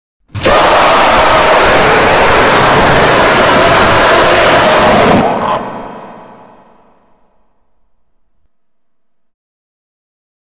ОЧЕНЬ ГРОМКИЙ ВОПЛЬ Картун Кэт (жуткий)